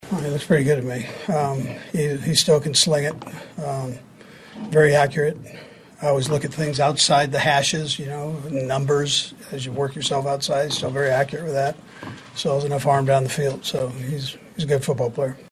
Chiefs Coach Andy Reid says Tom Brady still has elite skills.
10-1-andy-reid-on-tom-brady.mp3